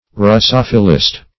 Search Result for " russophilist" : The Collaborative International Dictionary of English v.0.48: Russophile \Rus"so*phile\, Russophilist \Rus*soph"i*list\, n. [Russia + Gr. filei^n to love: cf. F. russophile.]
russophilist.mp3